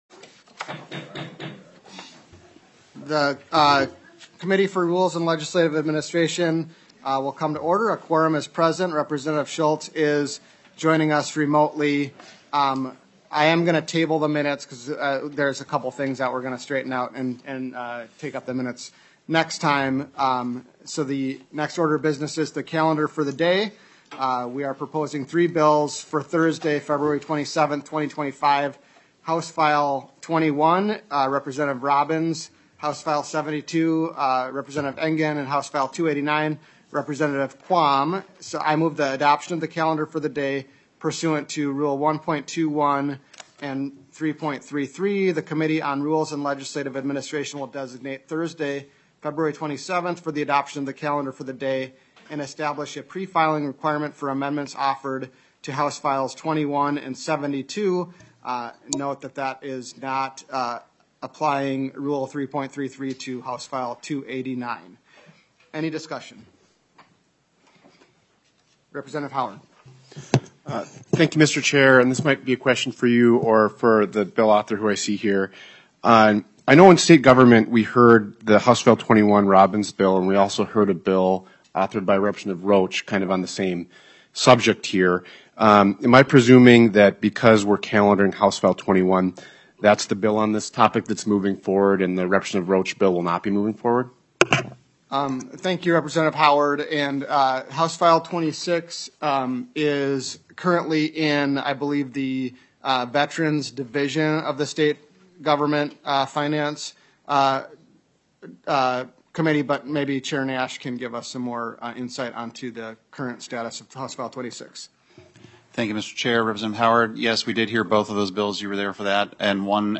Majority Leader Niska, Chair of the Rules and Legislative Administration Committee, called the meeting to order at 10:02 A.M. on February 25th, 2025, in Room G23 of the State Capitol.